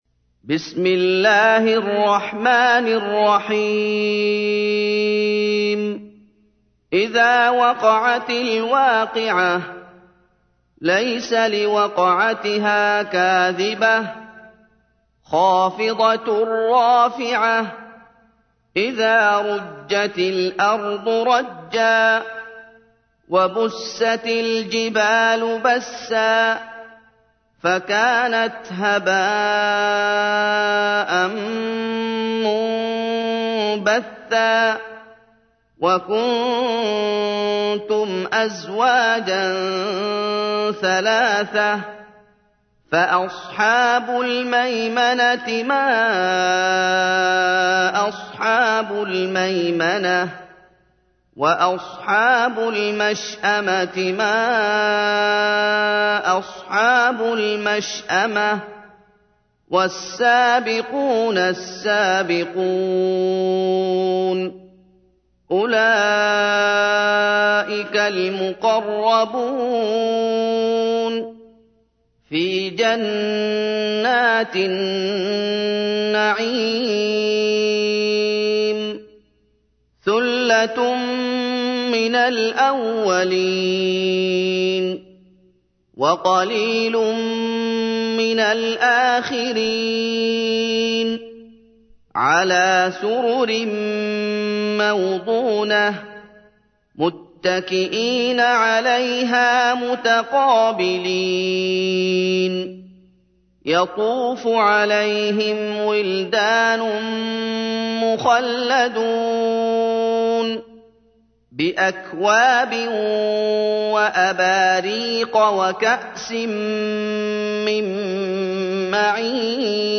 تحميل : 56. سورة الواقعة / القارئ محمد أيوب / القرآن الكريم / موقع يا حسين